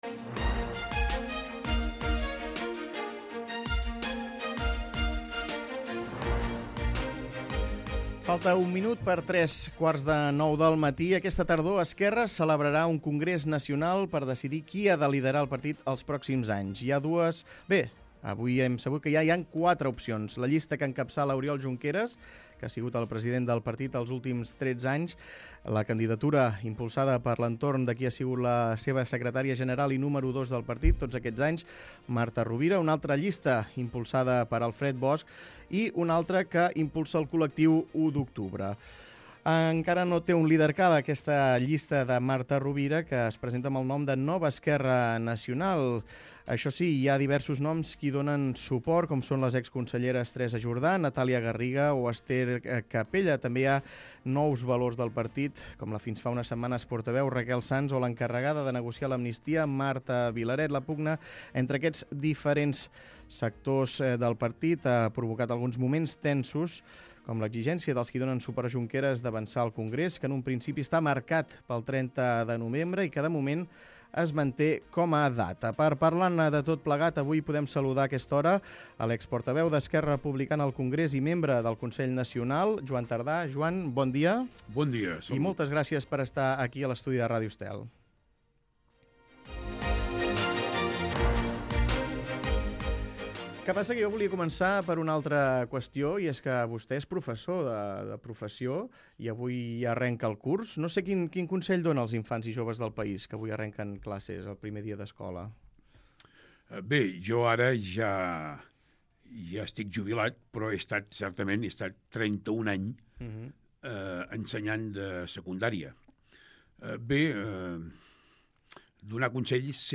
Ho ha dit en una entrevista a Ràdio Estel.
Escolta l'entrevista a Joan Tardà, membre del consell nacional d'Esquerra Republicana